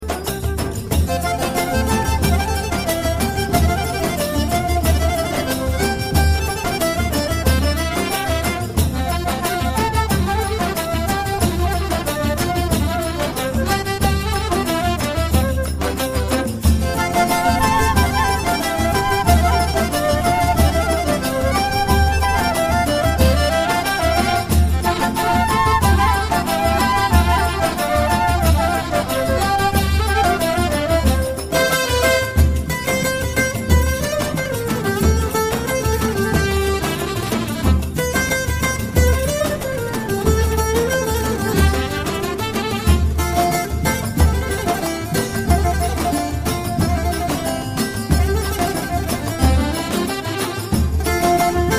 İnternet ortamında bulunmuş bir altyapı.